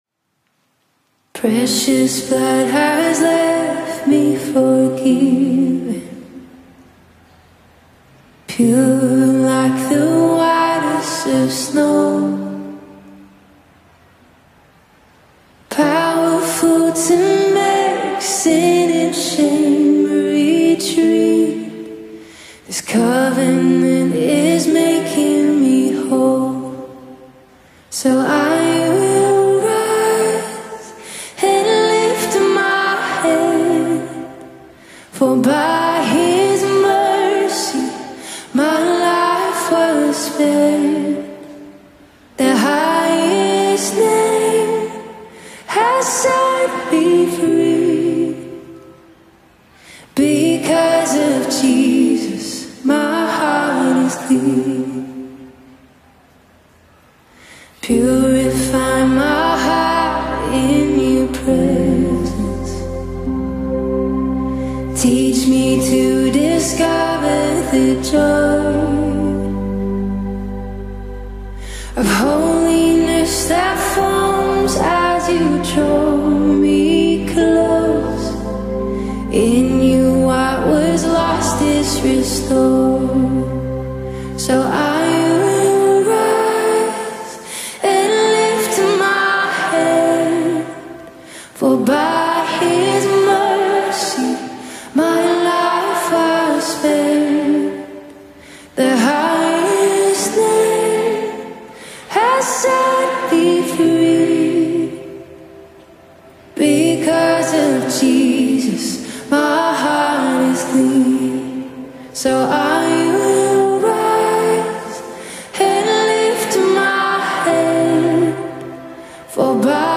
The worship band
” crooner brings to a new live performance song